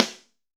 B.B SN 4.wav